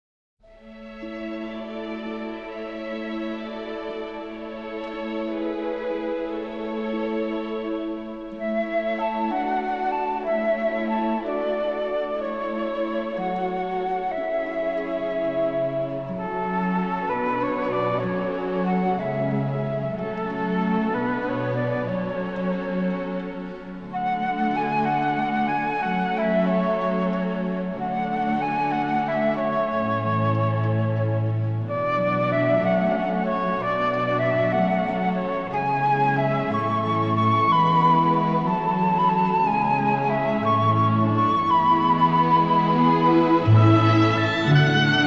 lovely, melodic scoring
newly remastered from the best possible stereo sources